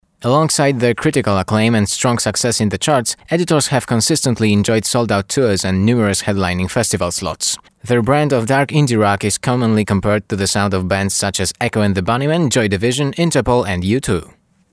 Male 20-30 lat
Demo lektorskie
Nagranie lektorskie w języku angielskim